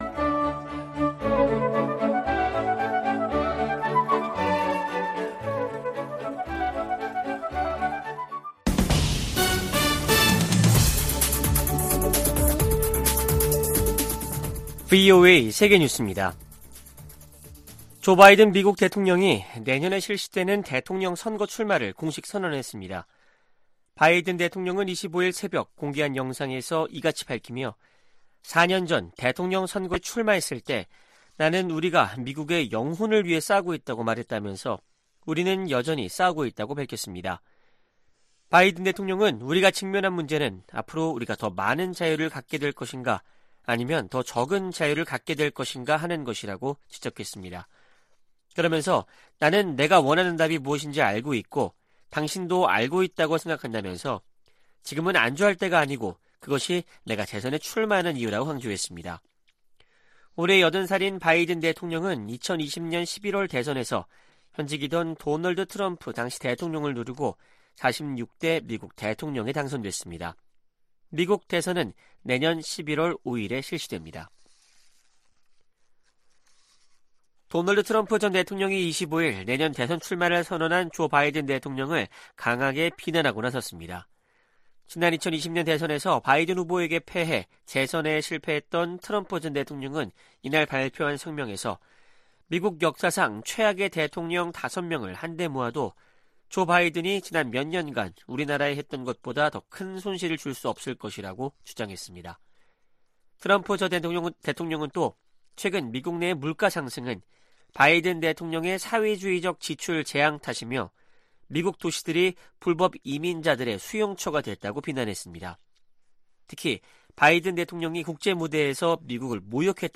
VOA 한국어 아침 뉴스 프로그램 '워싱턴 뉴스 광장' 2023년 4월 26일 방송입니다. 미국 국빈 방문 일정을 시작한 윤석열 한국 대통령은 미국과 한국이 '최상의 파트너'라며, 행동하는 동맹을 만들겠다고 강조했습니다. 존 커비 백악관 국가안보회의(NSC) 전략소통조정관은 미국의 확장억제 강화 방안이 미한 정상회담 주요 의제가 될 것이라고 밝혔습니다. 미 상원과 하원에서 윤 대통령의 국빈 방미 환영 결의안이 발의됐습니다.